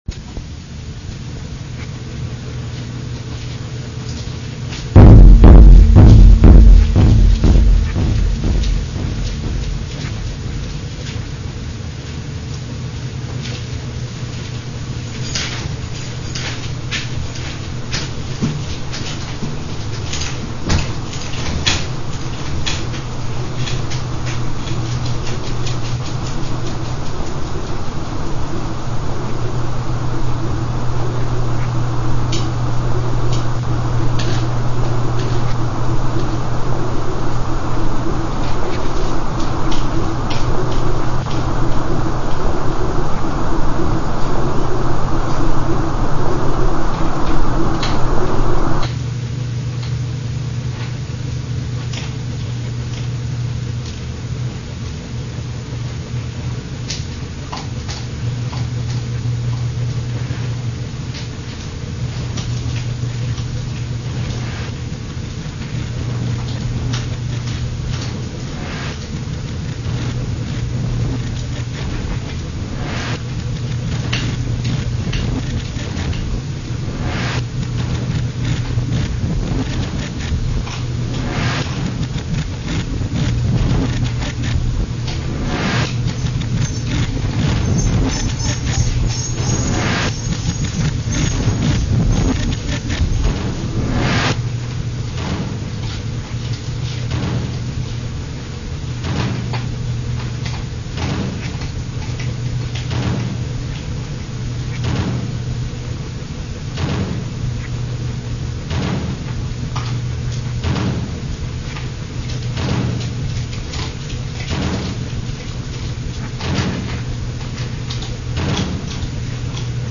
Noise/Sound Collage